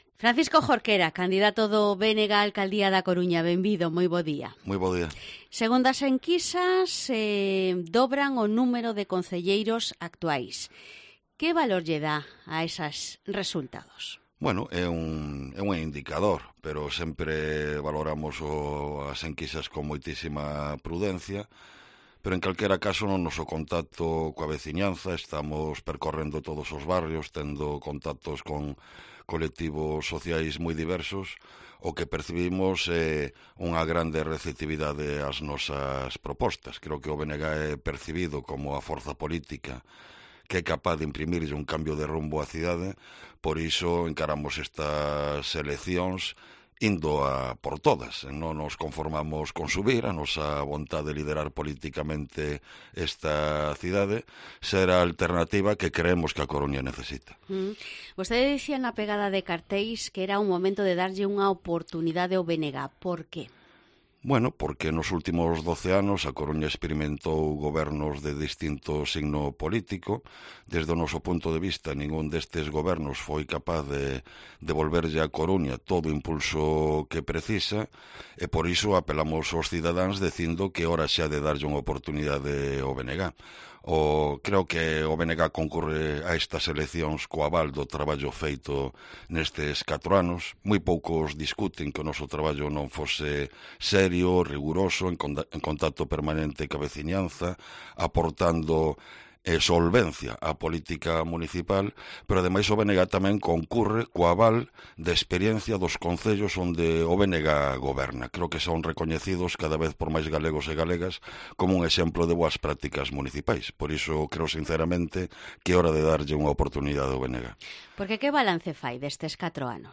Entrevista a Francisco Jorquera, candidato del BNG a la alcaldía de A Coruña